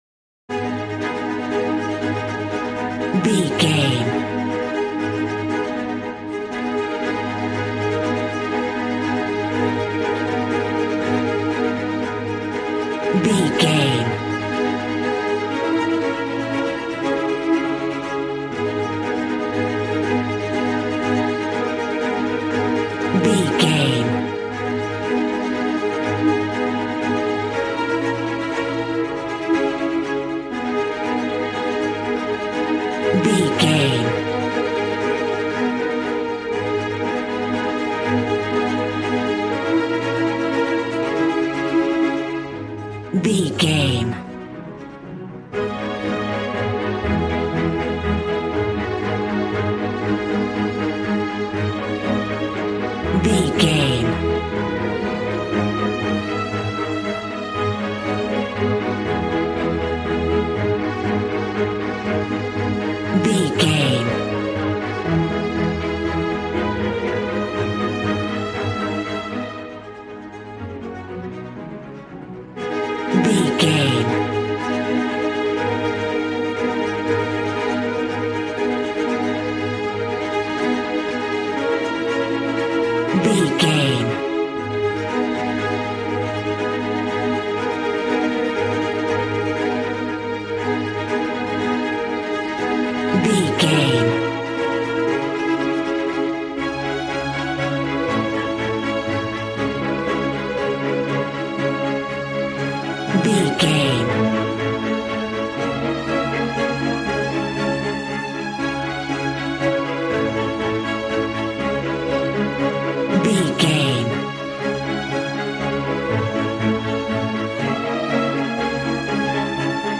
Aeolian/Minor
A♭
regal
strings
brass